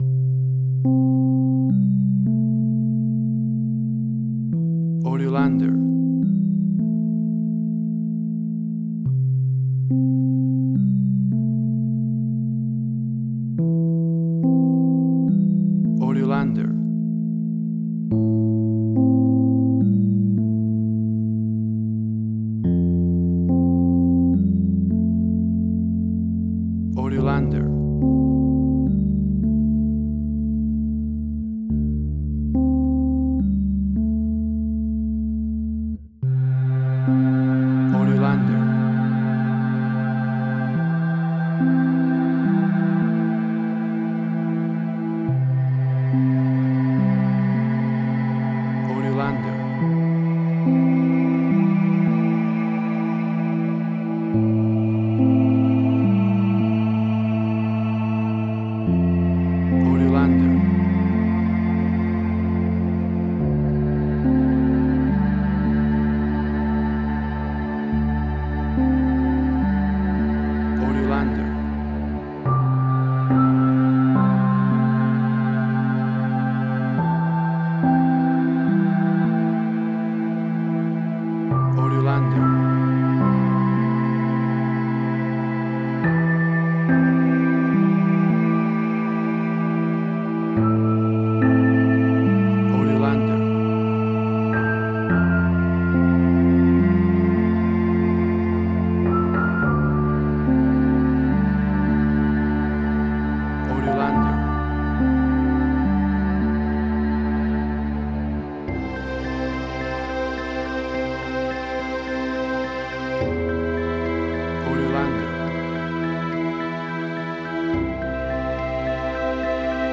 Indie Quirky.
Tempo (BPM): 106